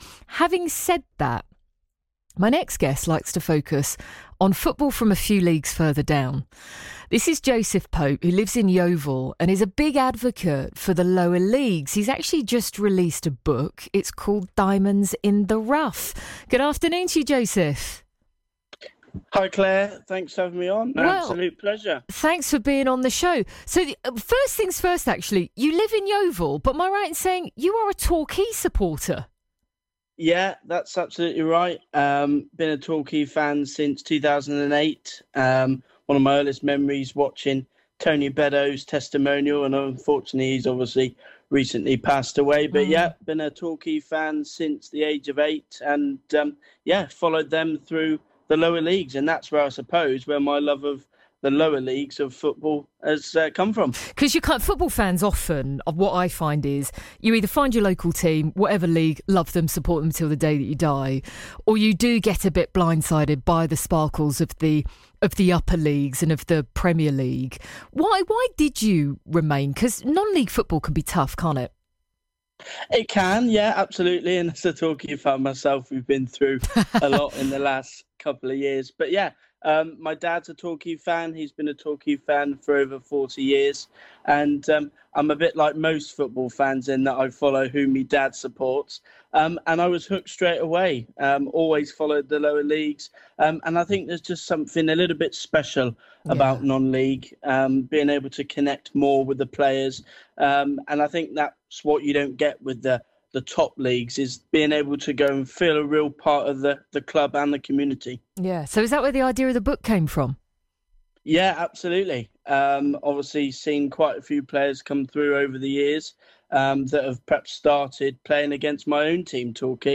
on BBC Radio Somerset